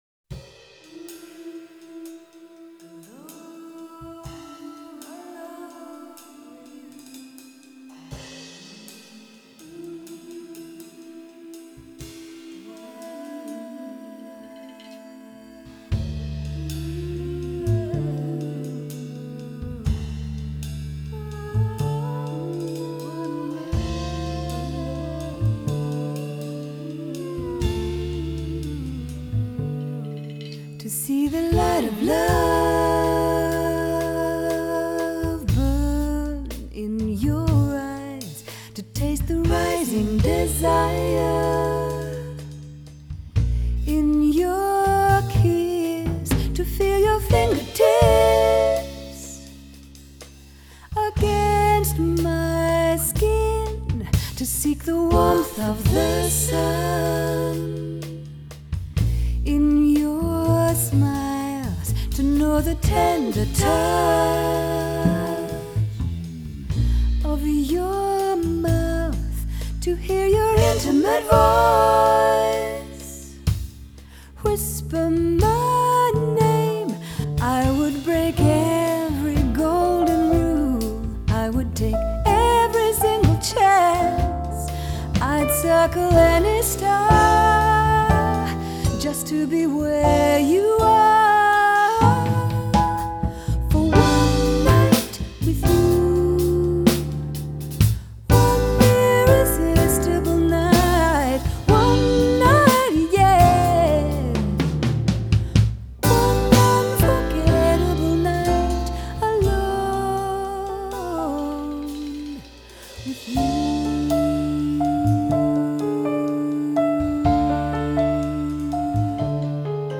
An uplifting auditory experience.